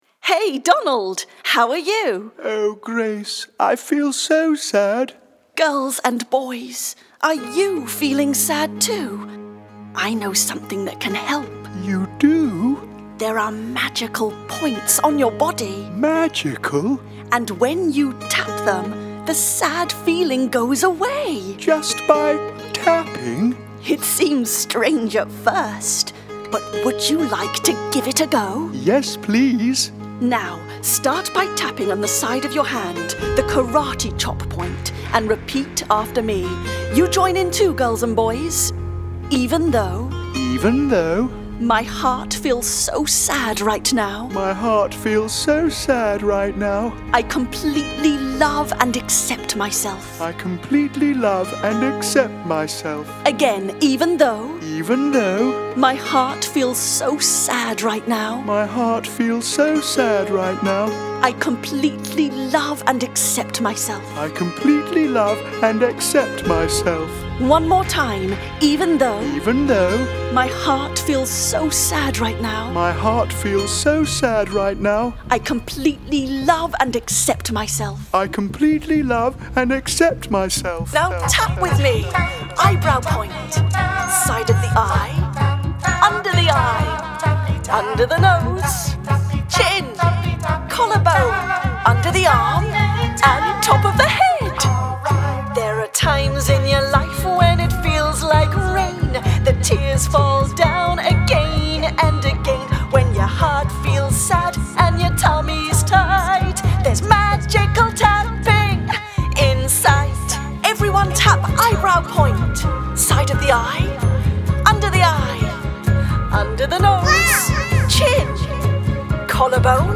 they have composed a fun song for children to learn and use EFT.